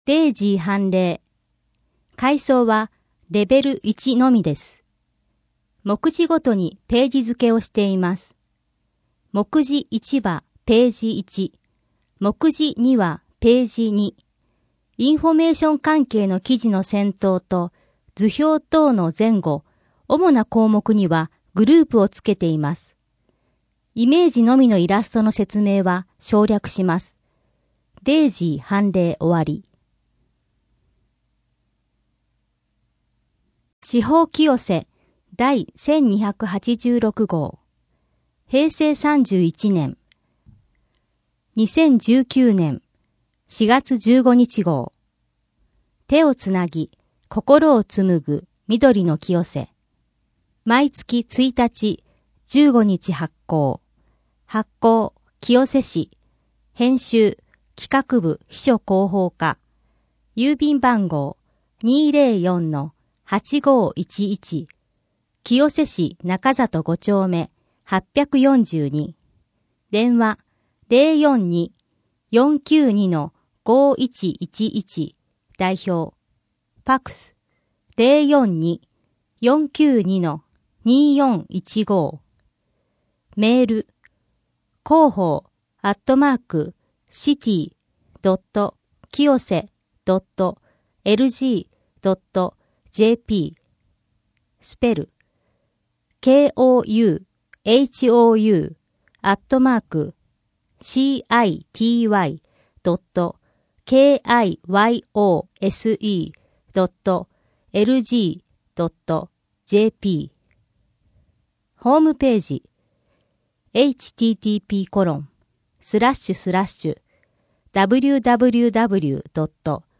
4月1日付人事異動 郷土博物館からのお知らせ 第19回テーマ展示「歴史と伝統のある地域・下宿編」 自然観察会 ミュージアム・シアター1 先人の知恵に学ぶ「ふせぎ作り講習会」 博物館事業スタンプラリー 図書館からのお知らせ 「子ども読書の日」に関する図書館事業 清瀬けやきホール・コミュニティプラザひまわり・NPO法人情報労連東京福祉センターの催し物 多摩六都科学館の催し物 人口と世帯 声の広報 声の広報は清瀬市公共刊行物音訳機関が制作しています。